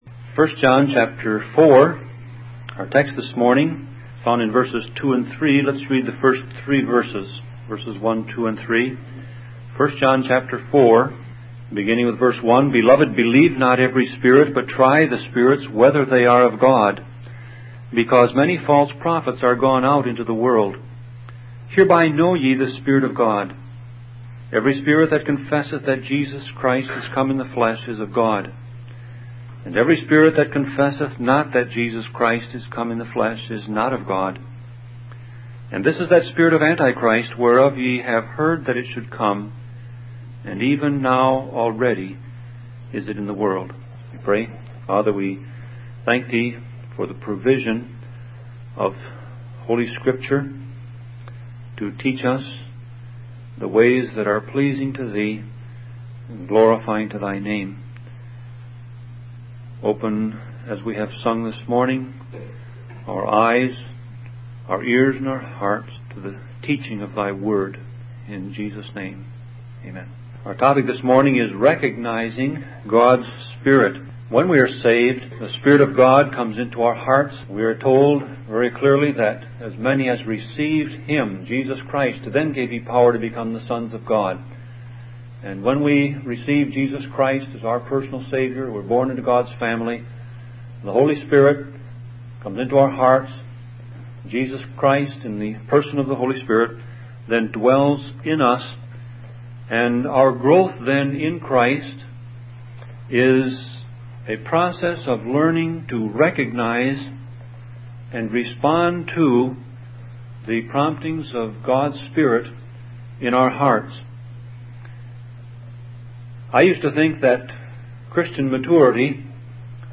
Sermon Audio Passage: 1 John 4:2-3 Service Type